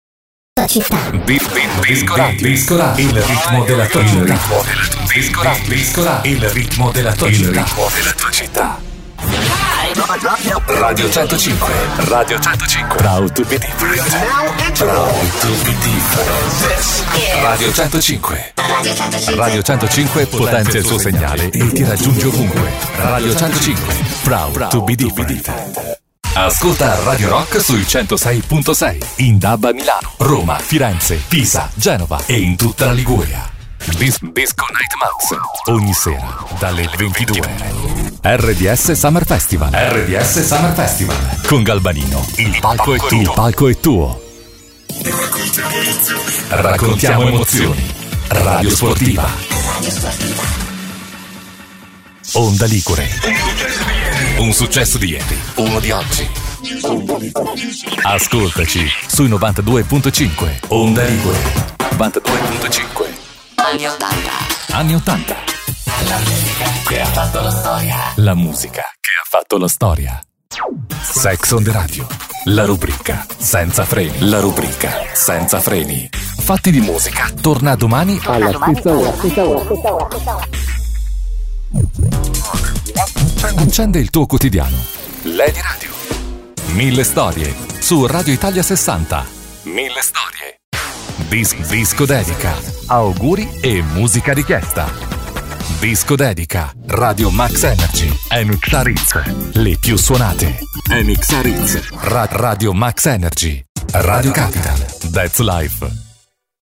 Male
My voice is young and versatile. Warm for narrations, dynamic for commercials, smooth and professional for presentations.
Radio / TV Imaging